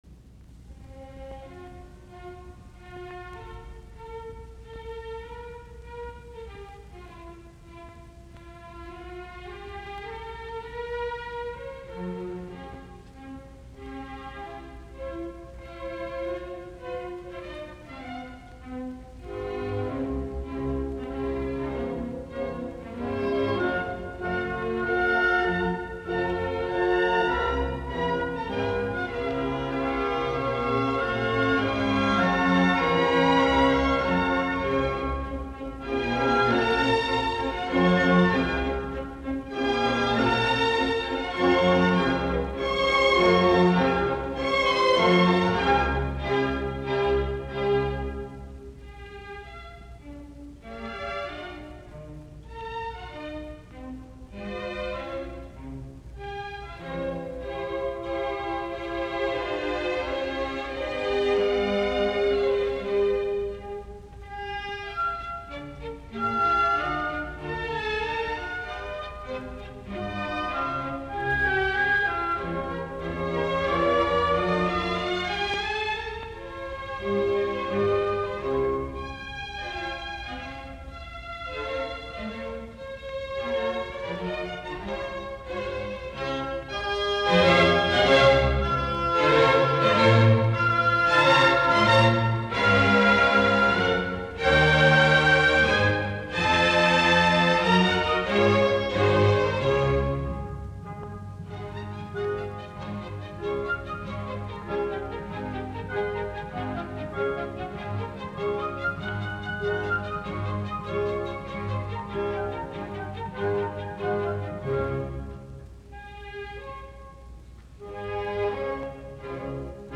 r1952, Carnegie Hall, New York.